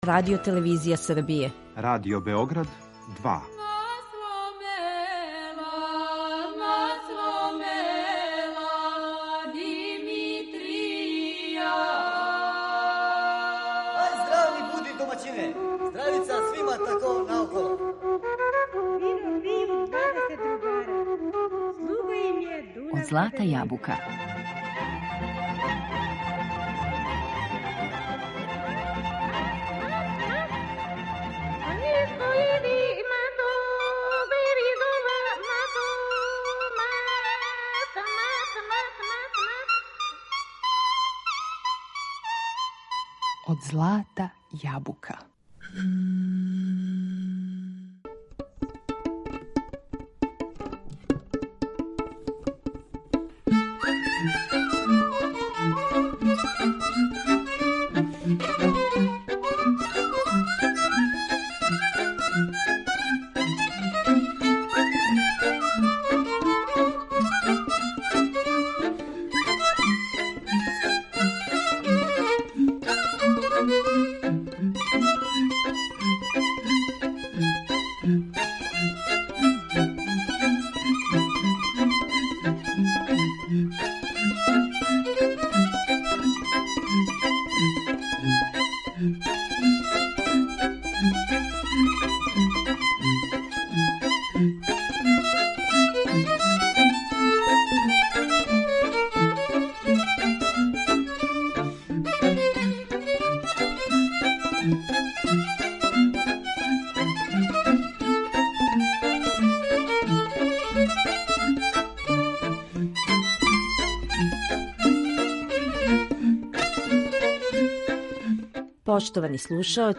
На репертоару данашње емисије су нумере уметника који су остварили велики успех на овој значајној листи као и учесници овогодишњег концертног дела програма WОМЕX-а.